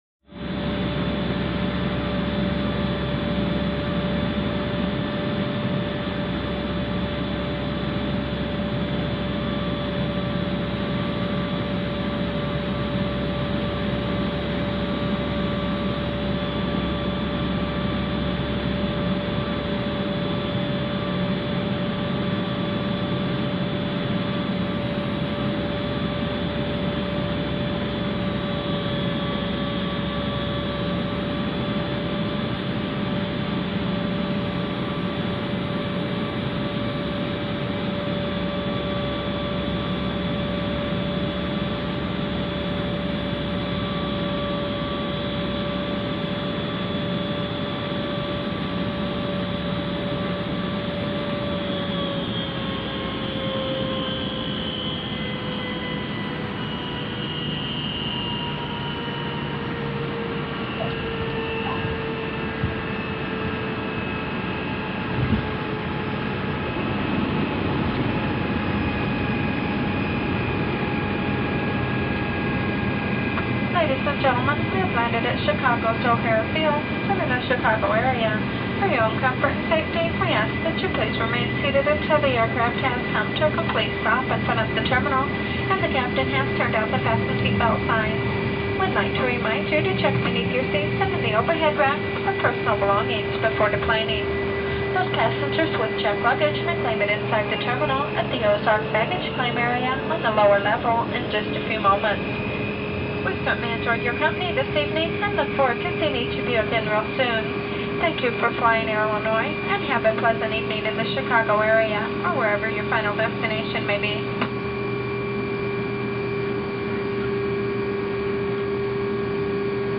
AIRCRAFT PROP TWIN TURBO: INT: Steady flight, descending, landing, taxi P.A. announcements.